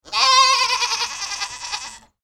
دانلود آهنگ بز 7 از افکت صوتی انسان و موجودات زنده
دانلود صدای بز 7 از ساعد نیوز با لینک مستقیم و کیفیت بالا
جلوه های صوتی